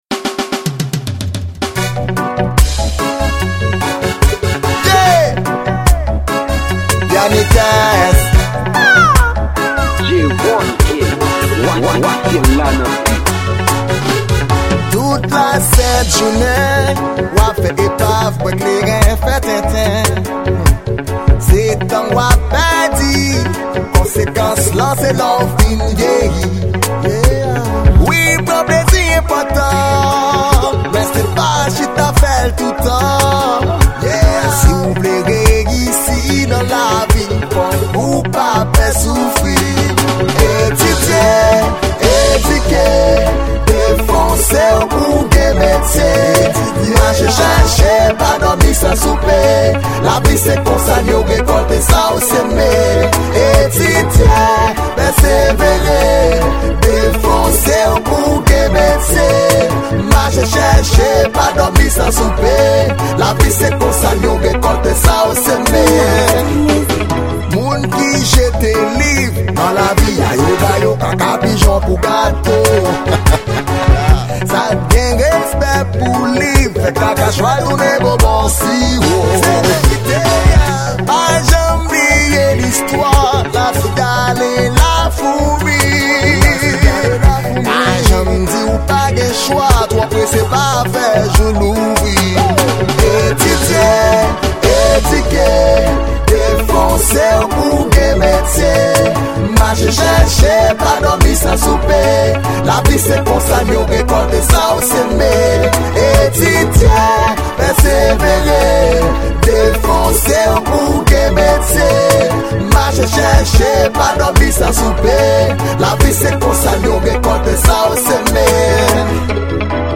Genre: Reggae.